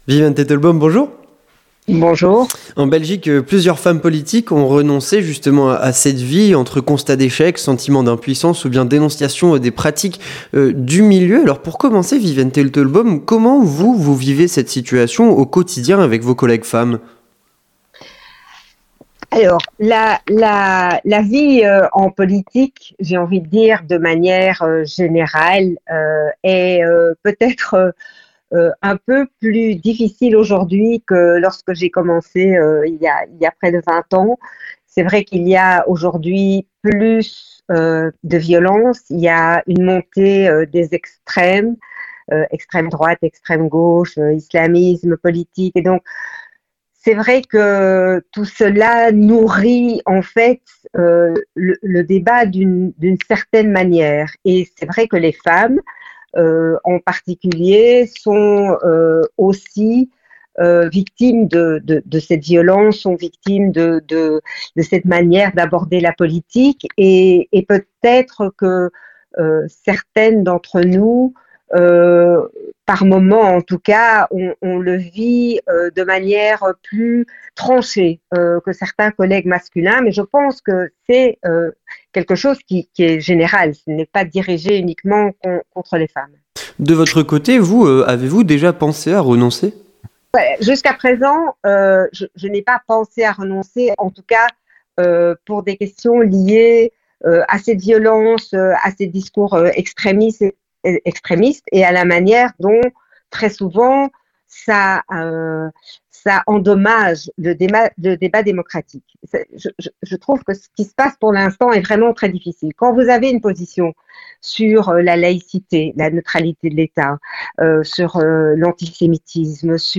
Avec Viviane Teitelbaum, députée bruxelloise MR